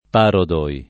parodo
parodo [ p # rodo ] s. m. o f. (teatr.); pl. i parodi (non le parodo )